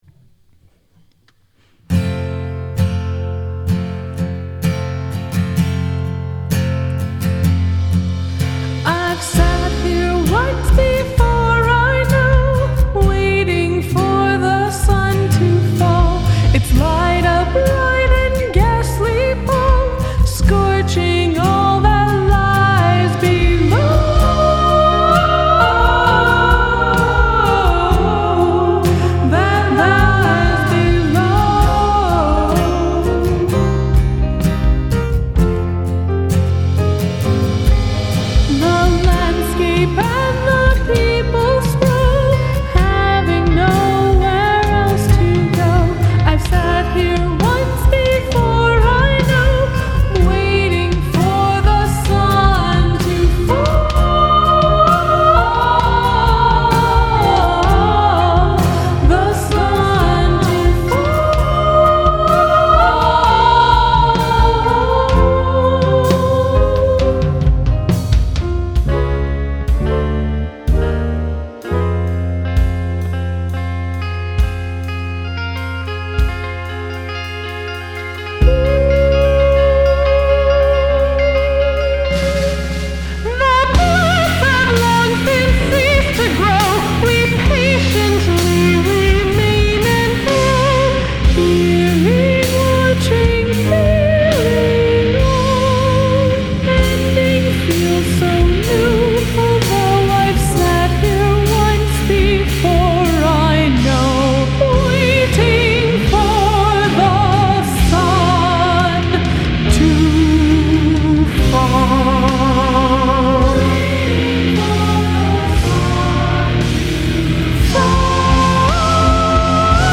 piano, bass